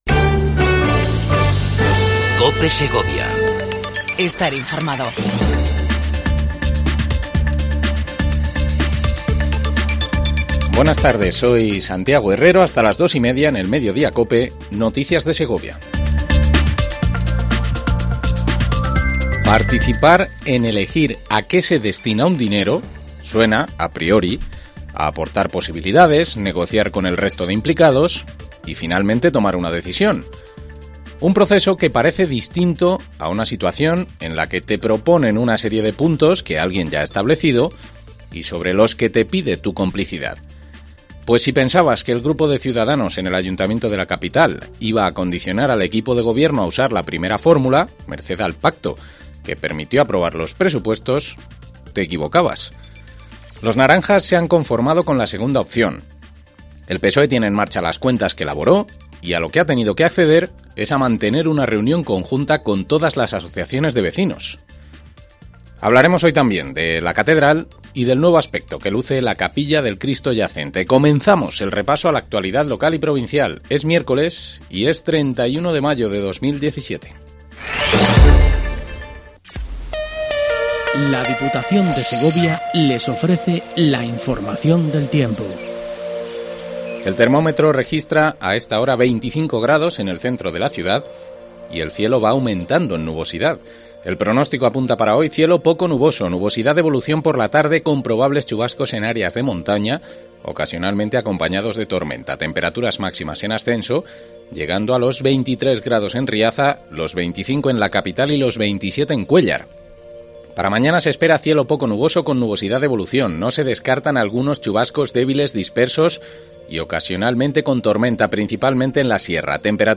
INFORMATIVO MEDIODIA COPE EN SEGOVIA 31 05 17